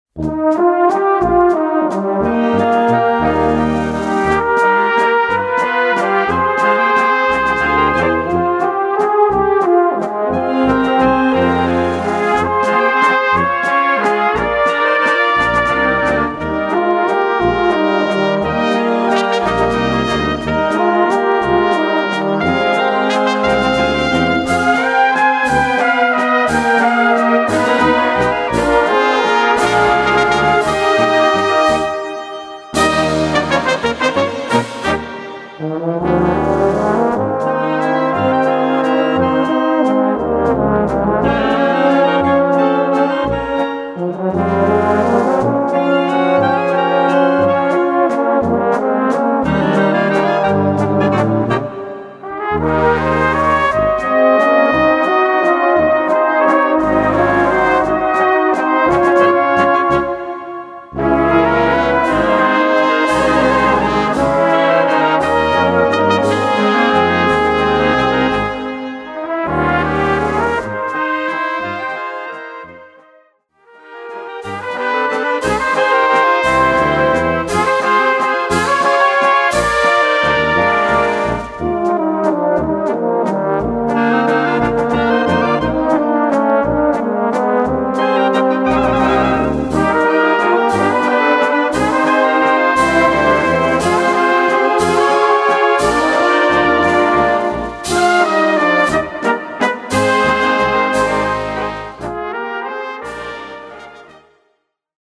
Gattung: Walzer
Besetzung: Blasorchester
Walzer für Egerländer Blasmusik
Ein Walzer zum Innehalten, Träumen und Genießen.